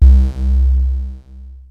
LIQUID MOOG 1.wav